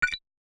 Simple Cute Alert 18.wav